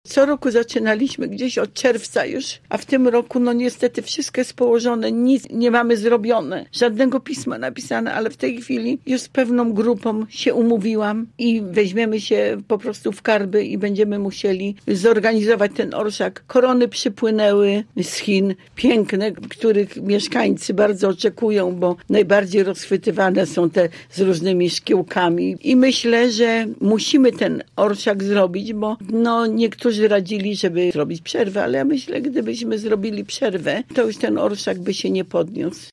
Były pomysły, żeby zrobić przerwę w organizacji imprezy – mówiła radna Prawa i Sprawiedliwości w Rozmowie Punkt 9: